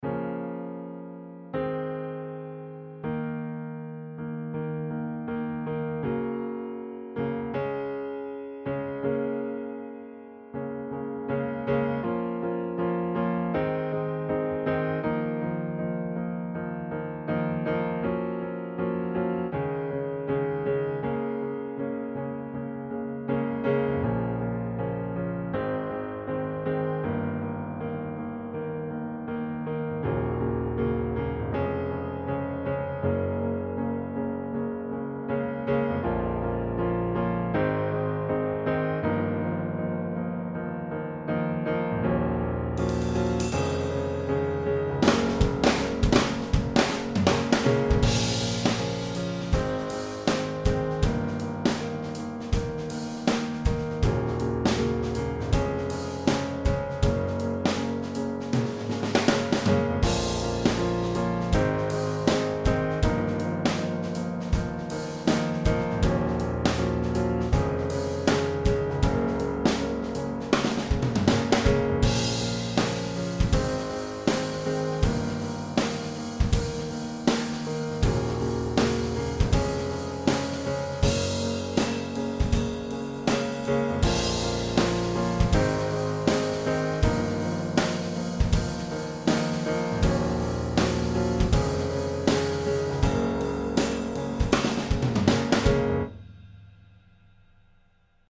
unfinished outro score that never got used